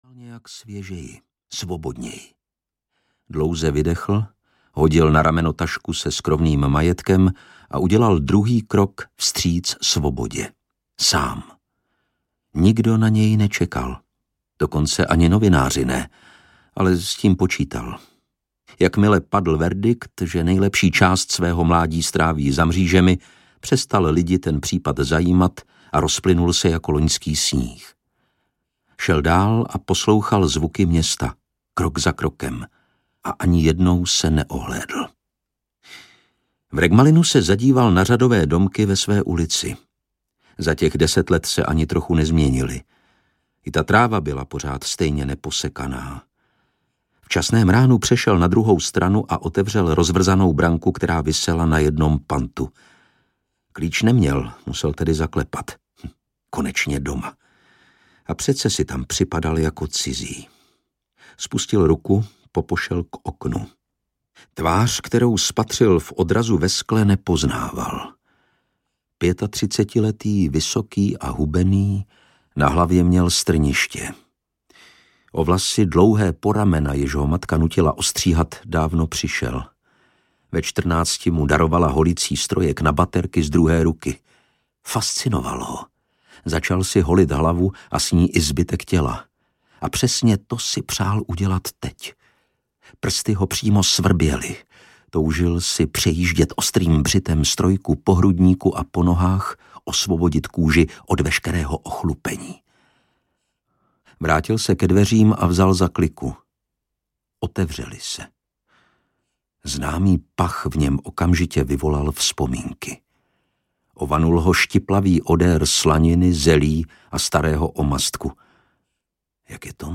Poslední zrada audiokniha
Ukázka z knihy
• InterpretLukáš Hlavica